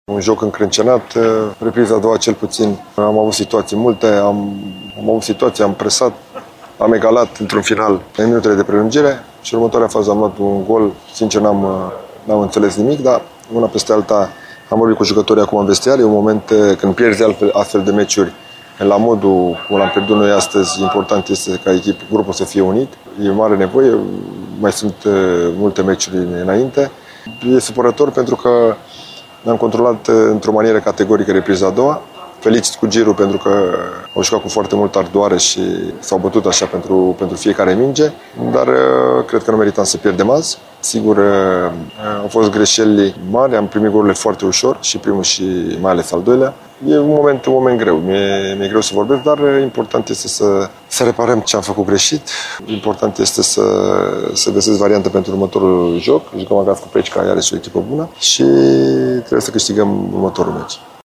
Antrenorul polist, Dan Alexa, consideră înfrângerea nedreaptă: